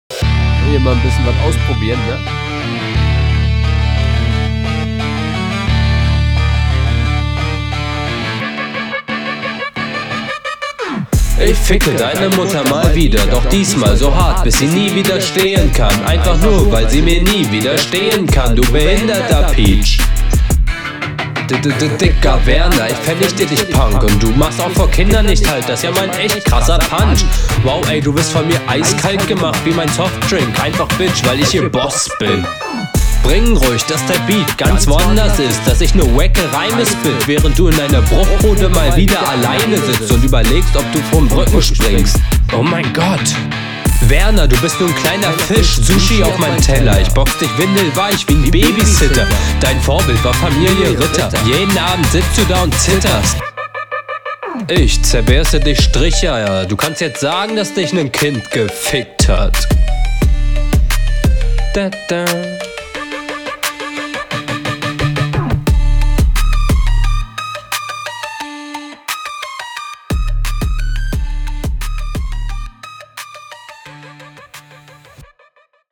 erschreckend viele konter (cool dass du den stutter effekt manuell einrappst)
Die Doubles machen es iwie unverständlich.
Beat bockt.